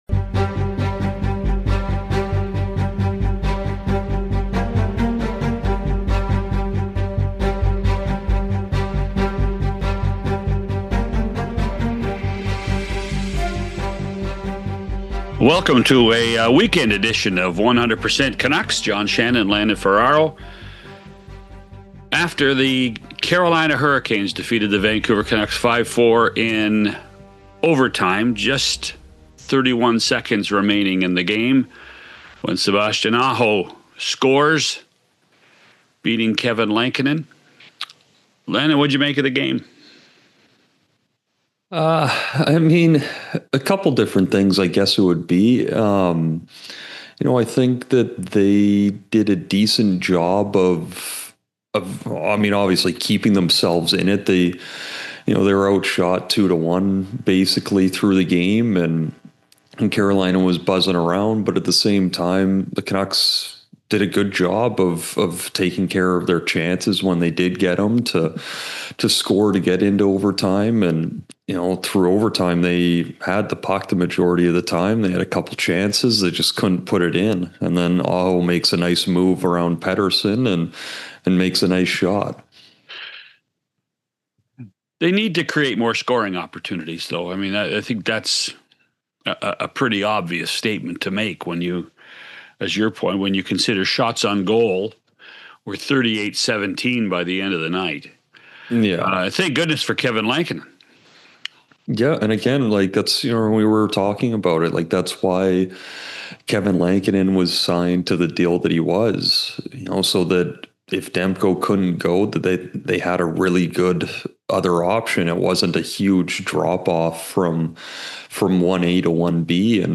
wide-ranging conversation